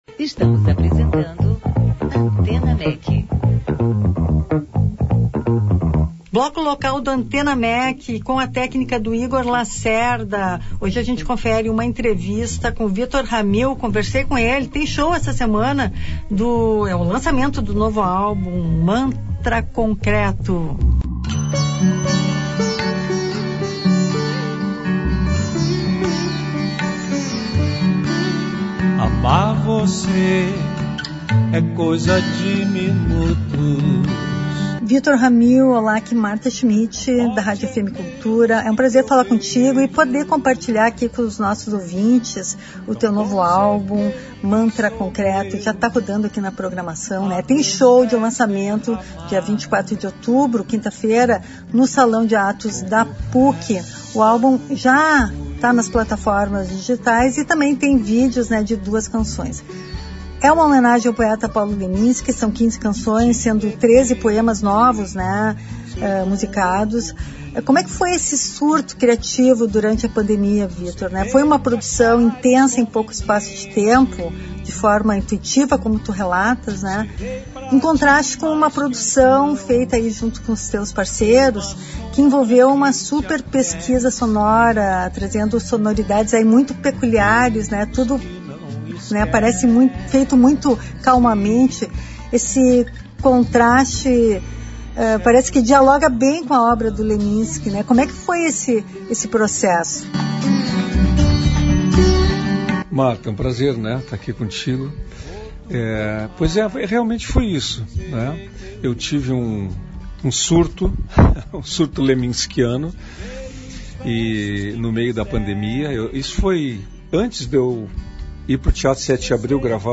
22/10/24-ANTENA MEC - TERÇA-FEIRA - ENTREVISTA COM VITOR RAMIL